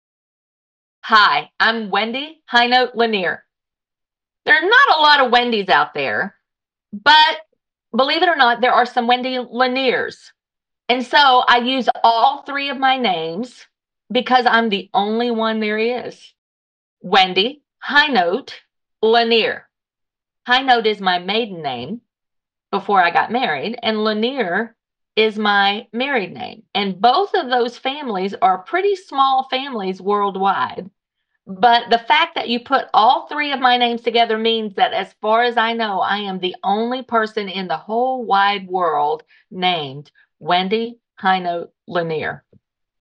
Audio Name Pronunciation